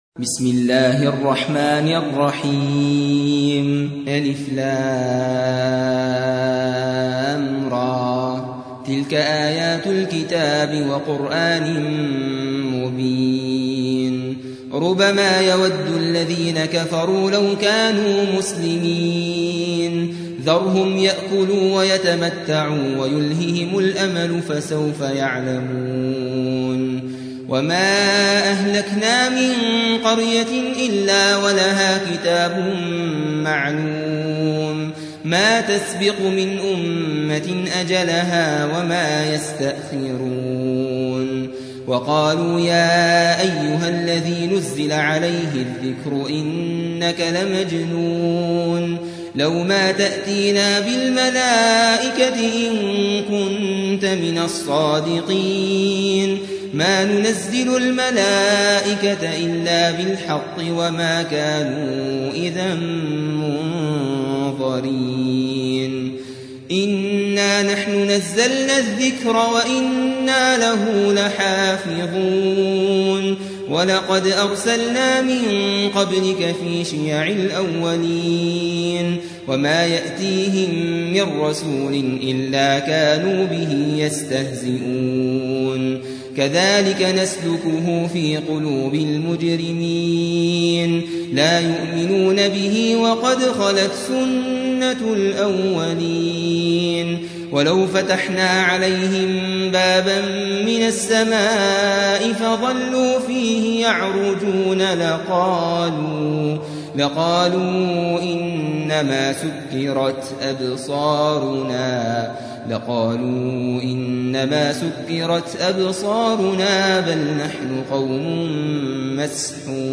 15. سورة الحجر / القارئ